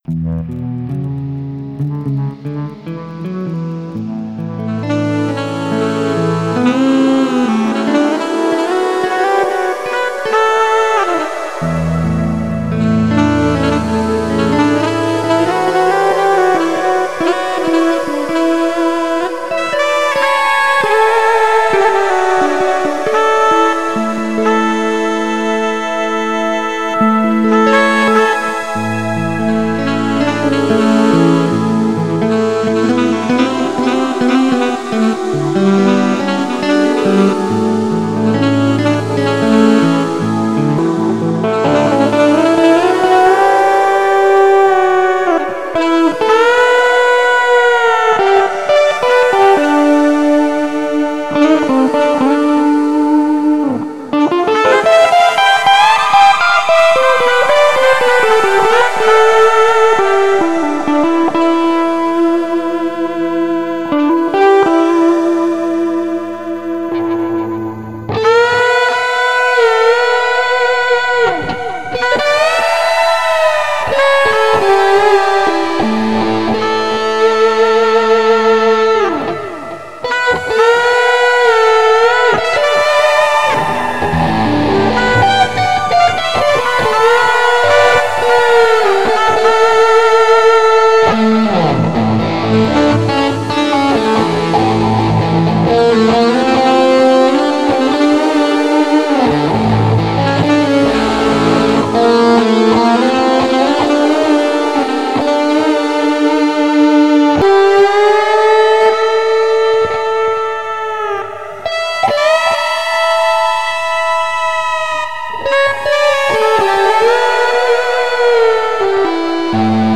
PISTES AUDIO (guitares & basse) + MIDI
DustySax (plus une ambiance qu'une compo - joué sur ma guitare MIDI )